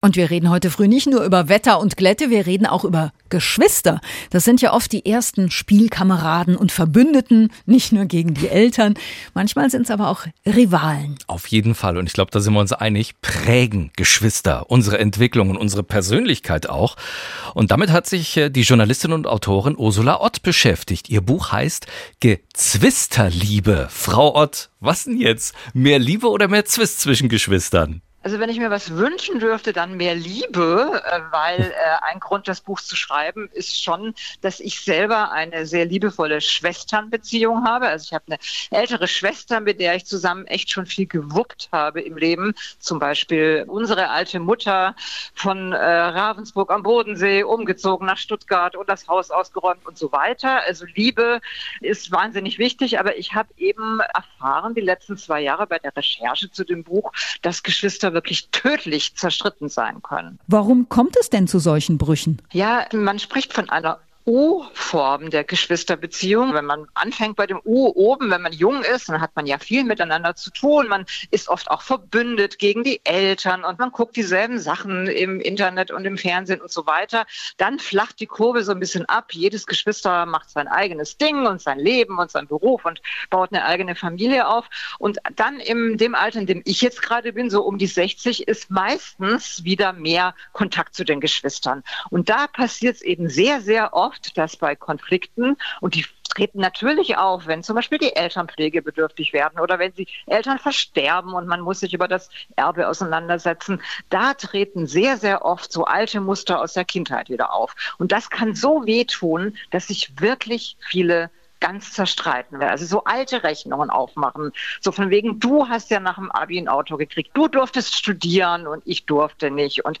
SWR1 Interviews